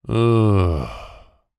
zapsplat_human_male_adult_sigh_groan_001_24847